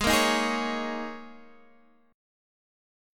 G#sus2sus4 Chord